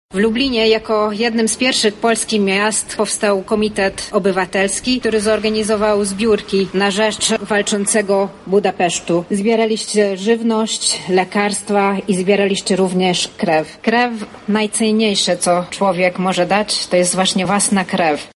W 1956 roku bezinteresownie wsparliście rewolucję węgierską – mówi ambasador Węgier w Polsce Orsolya Zsuzsanna Kovács: